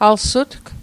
Pronunciation Guide: al·sutk Translation: He/she owns it